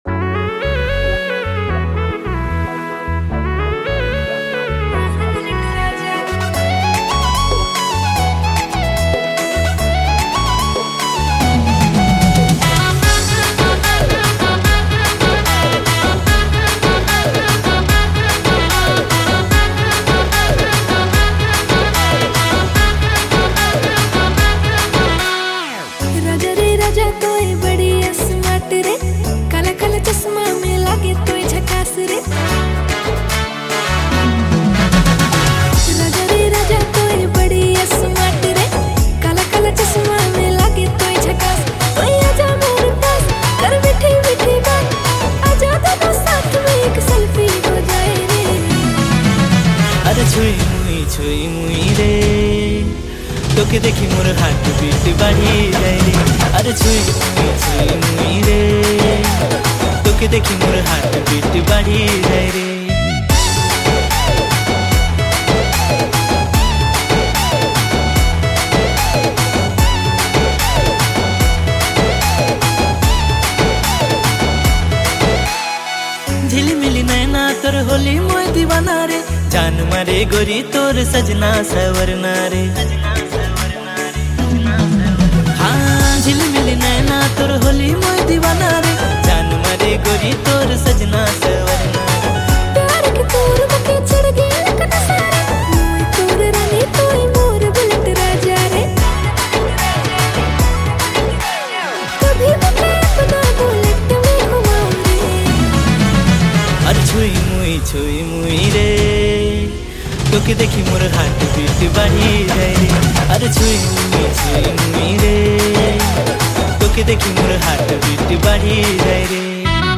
Dj Remixer
February Months Latest Nagpuri Songs